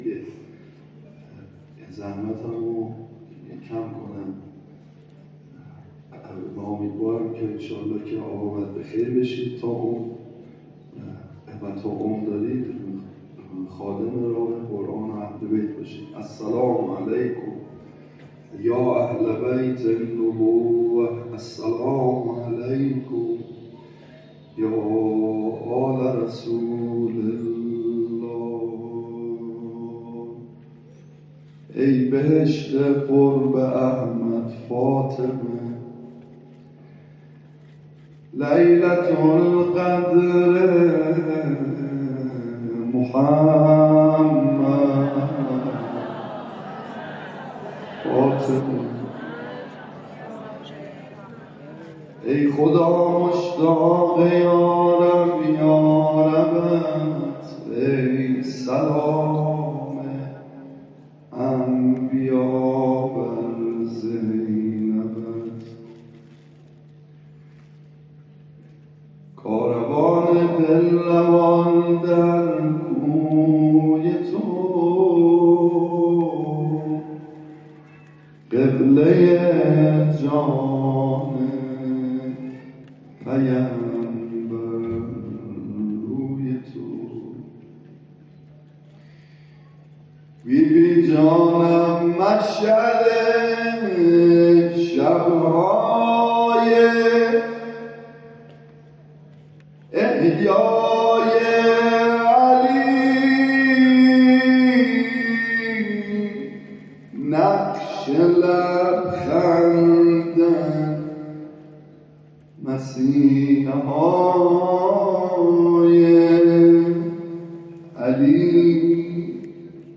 روضه خوانی
در تالار وحدت برگزار شد
به مداحی و روضه خوانی پرداخت.